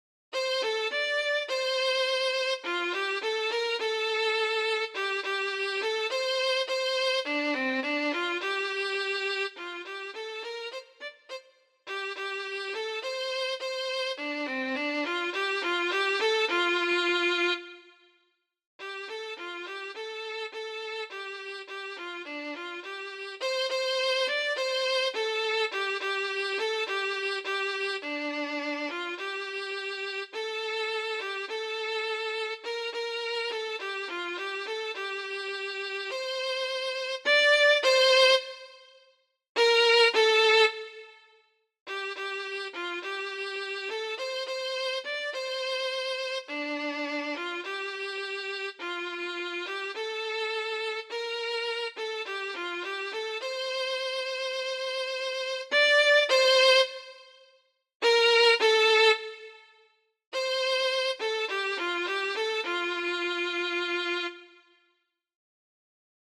基隆市德和國小校歌_配樂_小提琴演奏版.mp3